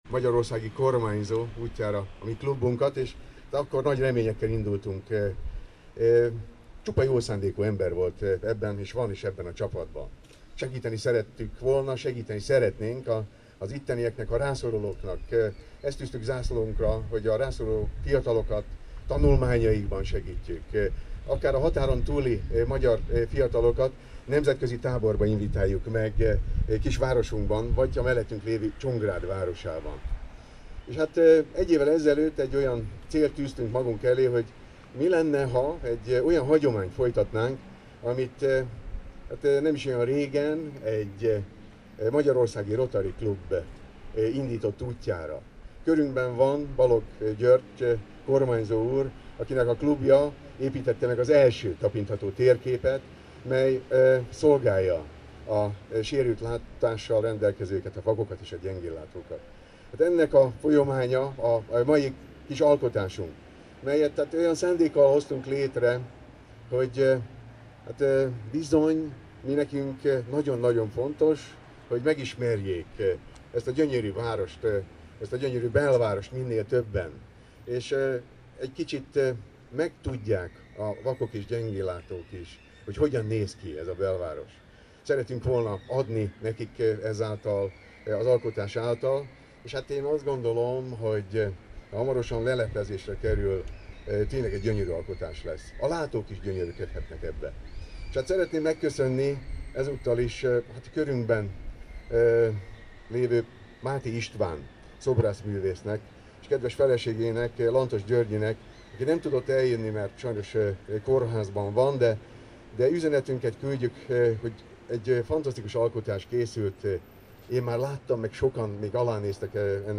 beszéde.